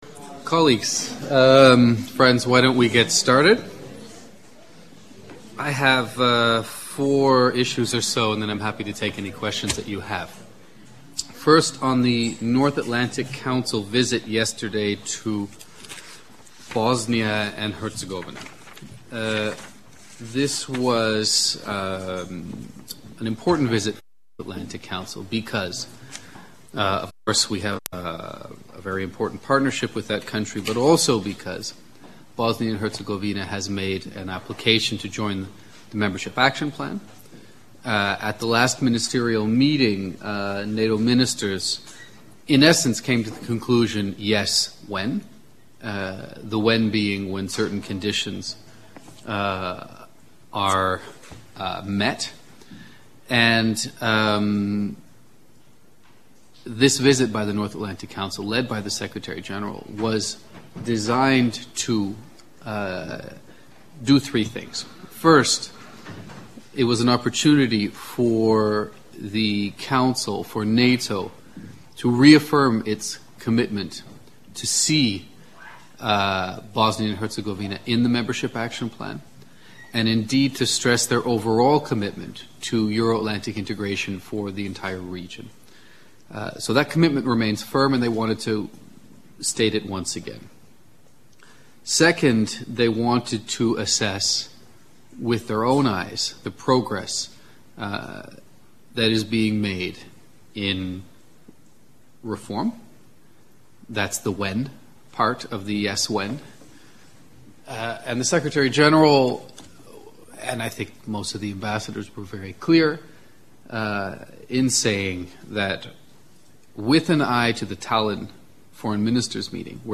Weekly press briefing